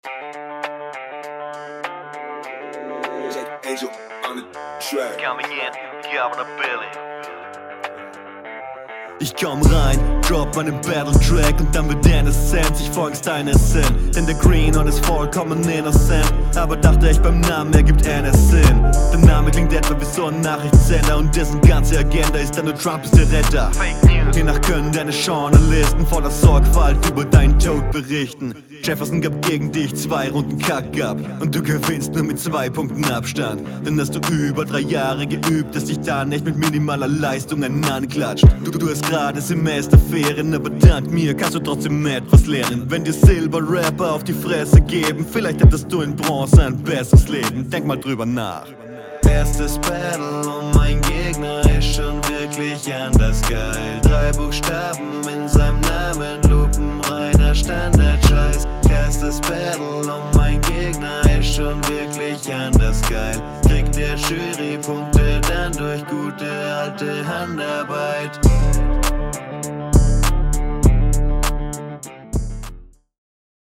Hook ist nice.